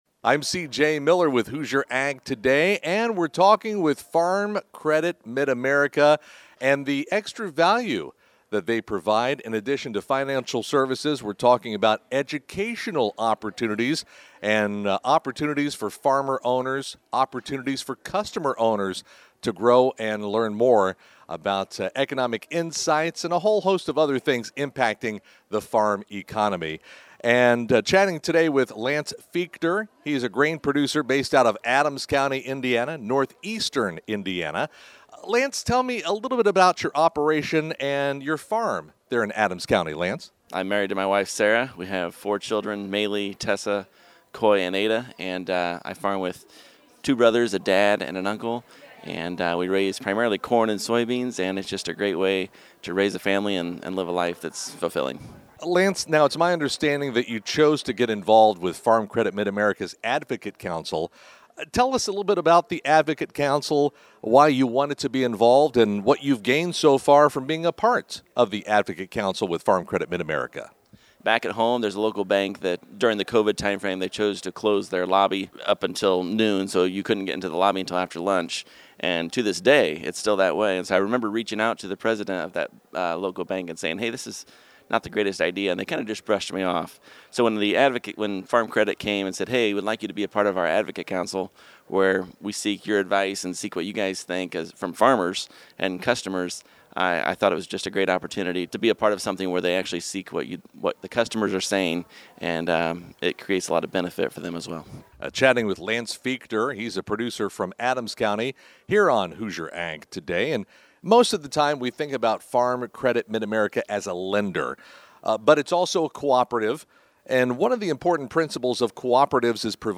full conversation